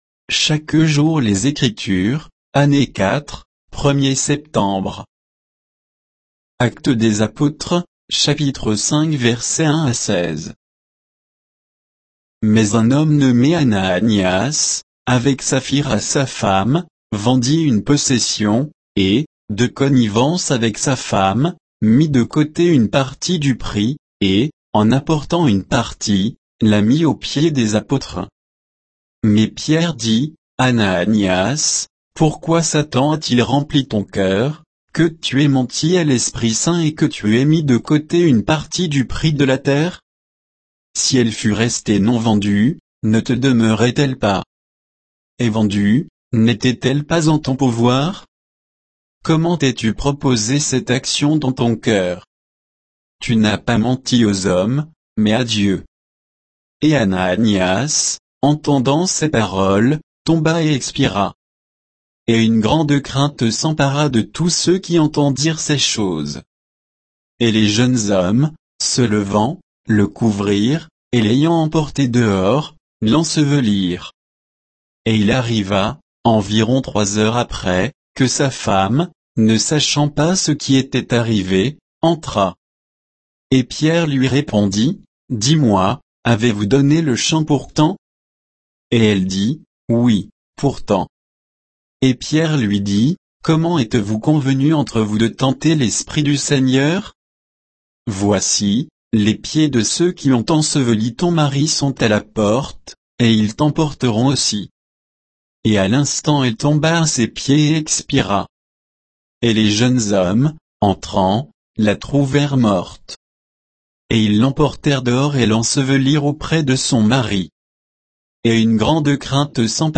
Méditation quoditienne de Chaque jour les Écritures sur Actes 5, 1 à 16